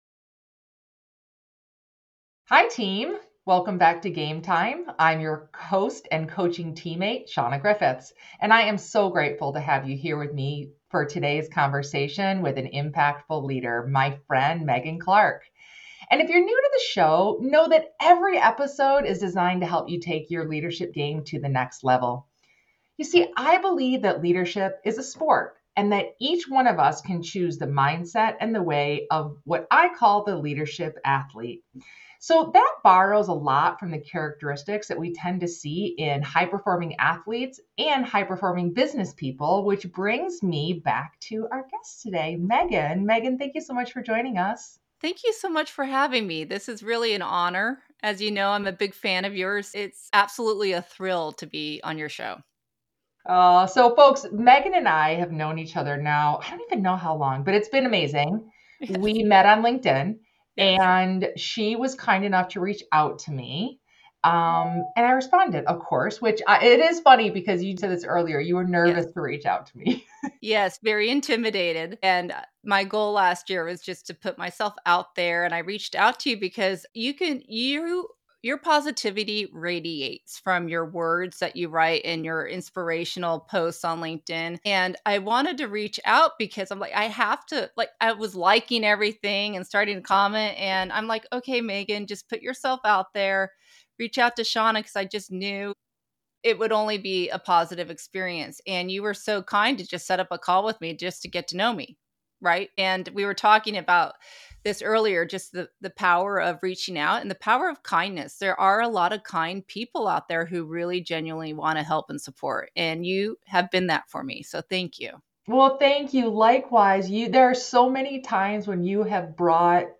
CONVERSATIONS WITH IMPACTFUL LEADERS.